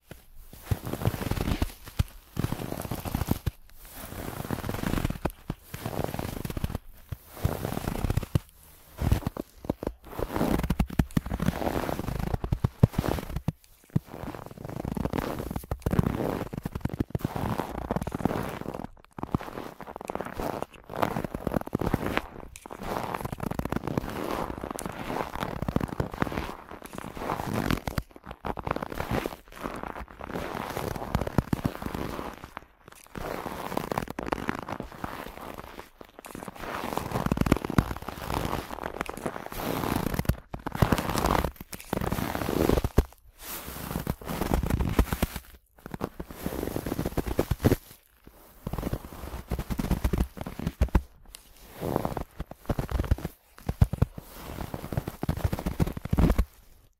Le son est donc enregistré avec tous les paramètres de filtrage, de réflexions et de diffractions, en fonction de sa provenance et de la position du micro.
Snow-Step-Edition-Blog.wav